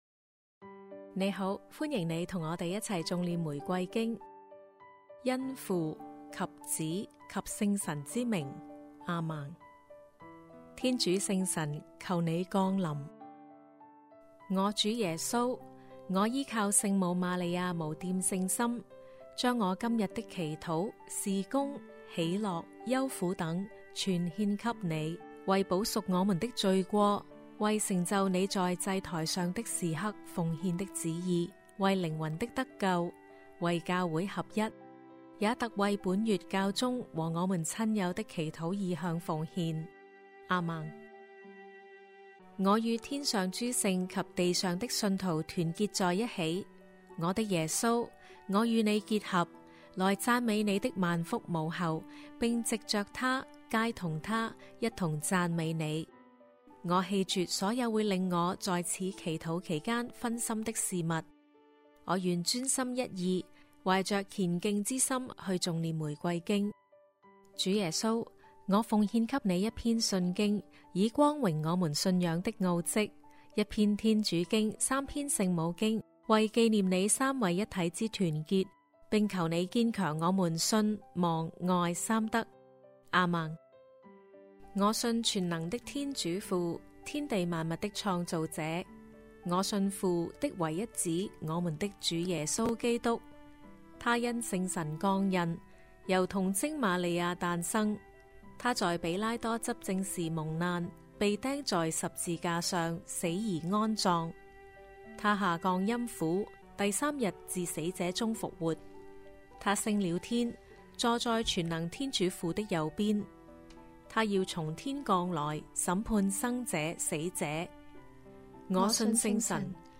童聲齊頌玫瑰經：歡喜五端 *